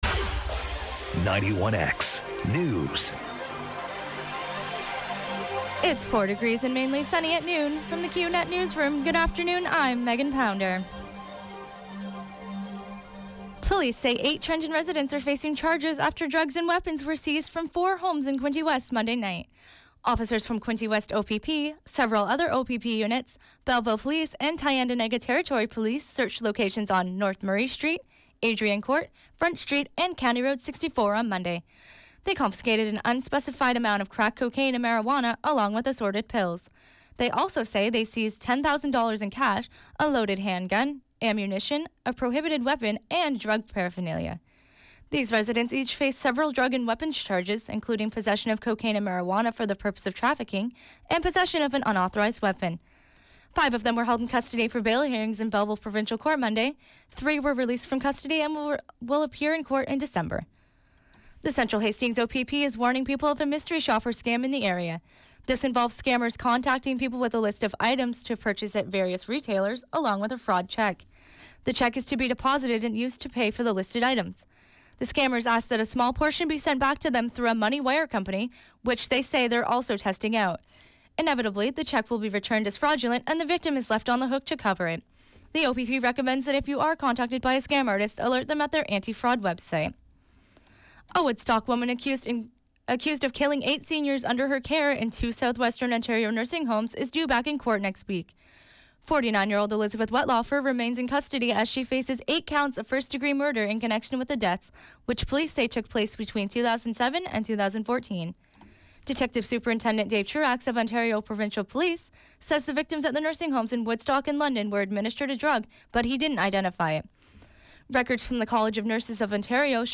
91X Fm Newscast – Wednesday, Oct. 26, 2016, 12 p.m.